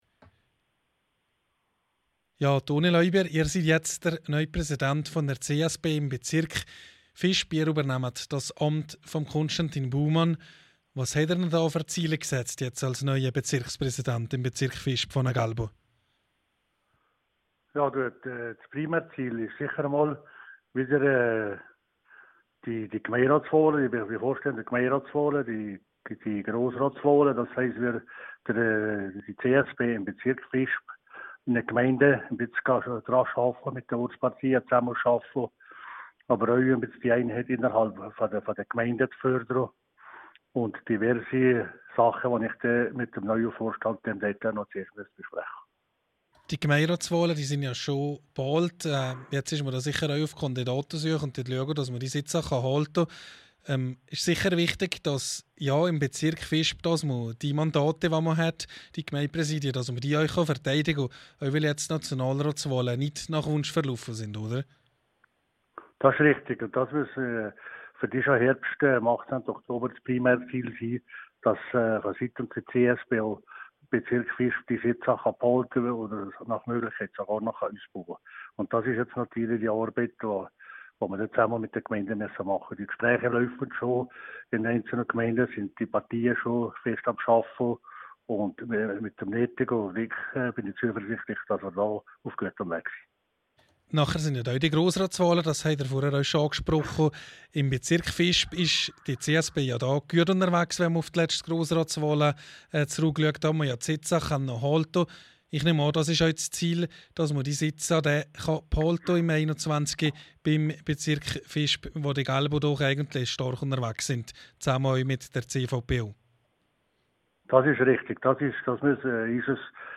Ob Anton Lauber, der seit 2013 im Grossrat für die Christlichsozialen politisiert, nochmals antritt, entscheidet sich in den nächsten Wochen, wie er gegenüber rro erklärte./wh/vm Interview mit dem neuen Präsidenten der CSP Bezirk Visp, Anton Lauber.